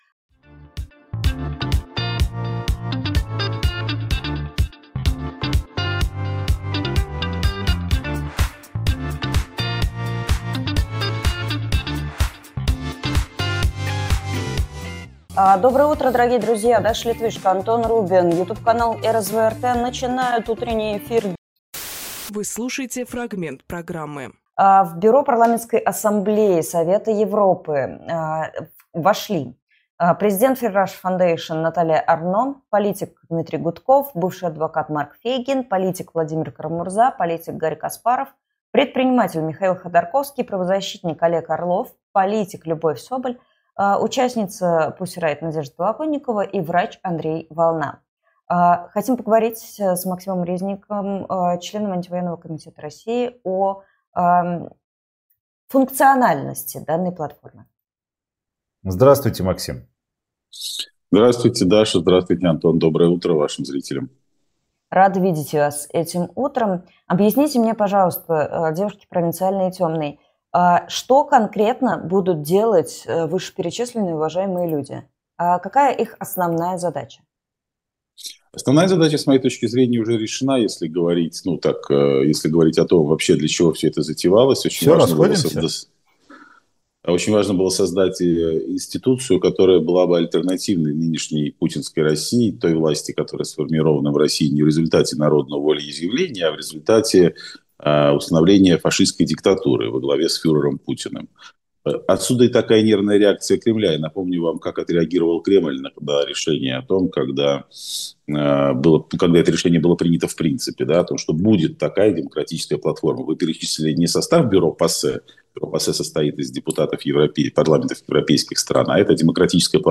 Фрагмент эфира от 27.01.26